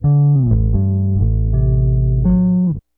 BASS 11.wav